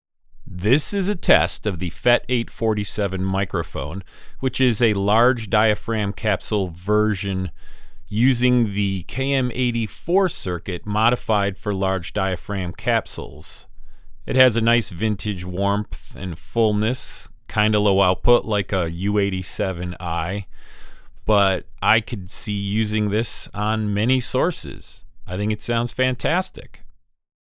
Here is some audio from that FET847. This is me speaking a bit.